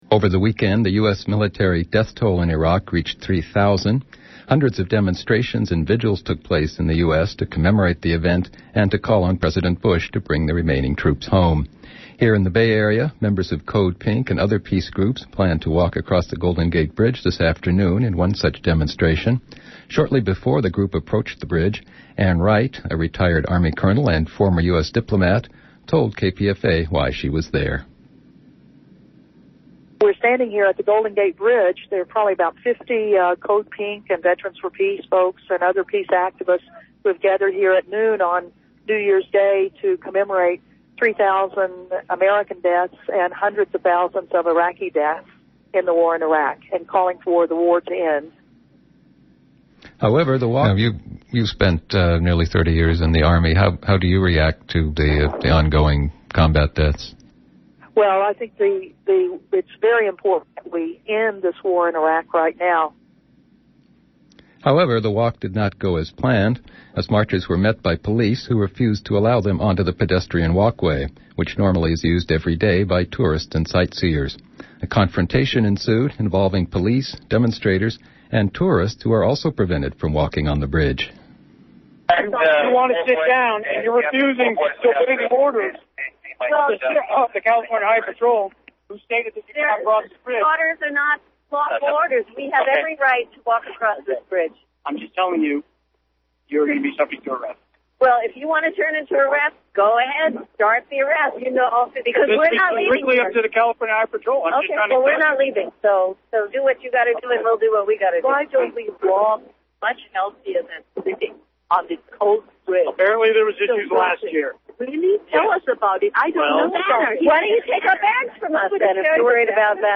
CODEPINK Arrests - Eyewitness KPFA Report
Four-minute excerpt of Evening News, January 1, 2007. 9.5mb